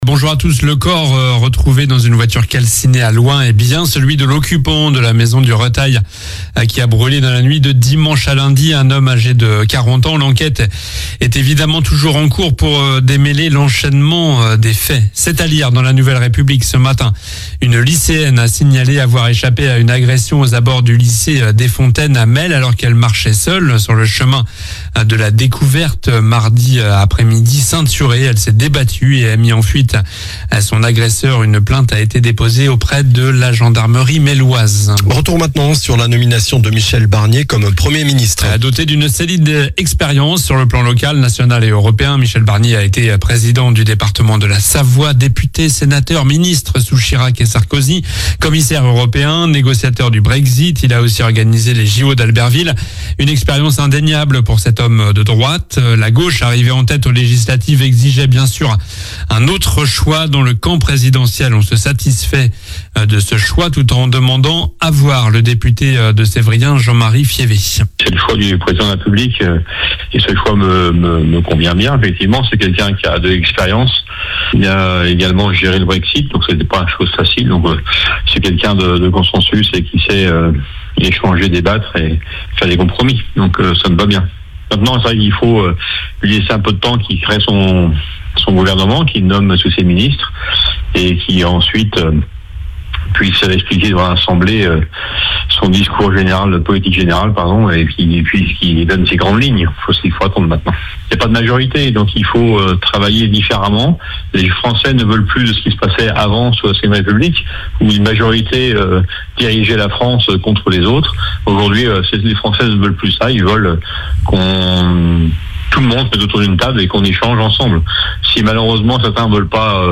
Journal du samedi 7 septembre (matin)